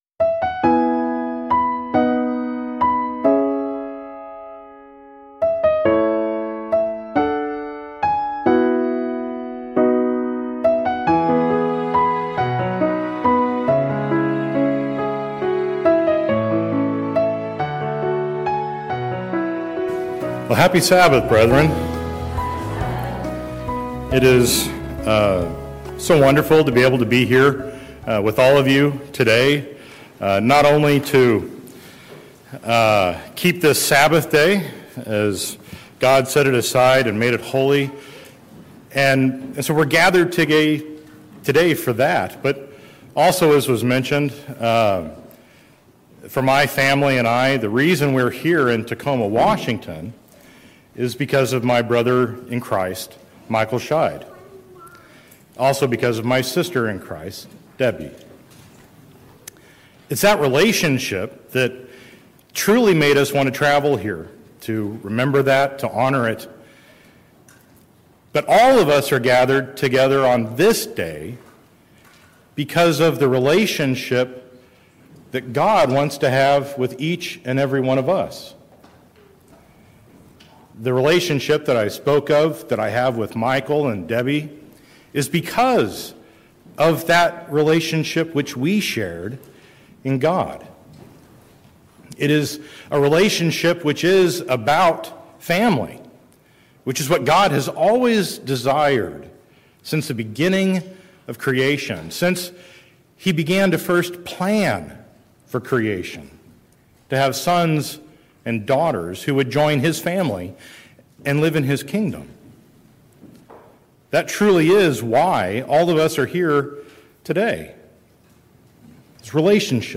True friendship with God reshapes priorities, redefines faithfulness, and demands personal transformation. This sermon challenges every believer to examine whether they are merely acquainted with God—or genuinely walking with Him.